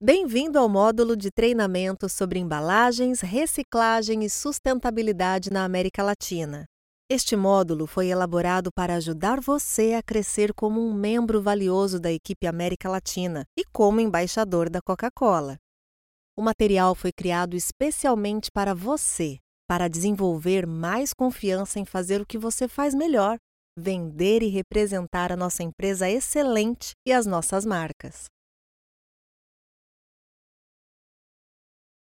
Female
Approachable, Assured, Bright, Cheeky, Conversational, Corporate, Deep, Friendly, Natural, Posh, Smooth, Warm, Young
My accent is considered neutral, with a soft memory of São Paulo prosody.
Warm, youthful, and highly professional, my voice delivers a natural and conversational performance with a neutral accent.
Microphone: AKG P220